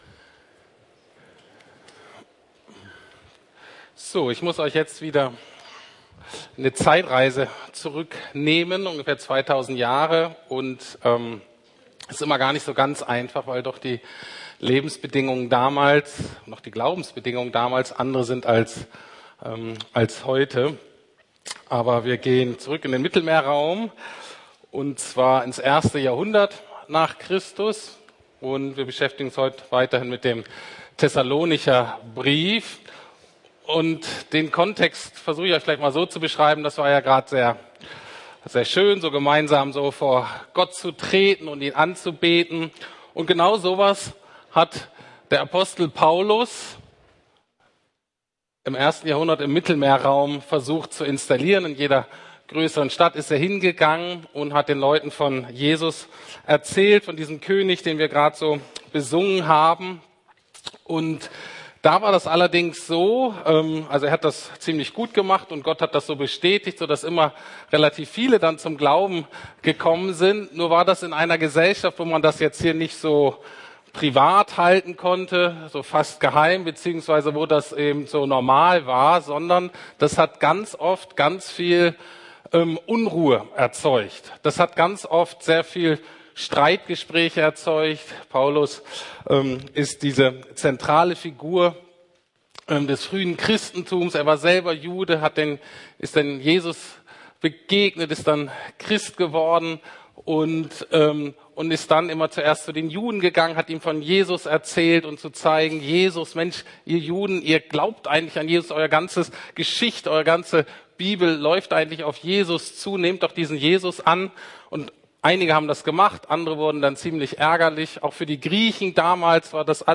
Trost in Bedrängnis ~ Predigten der LUKAS GEMEINDE Podcast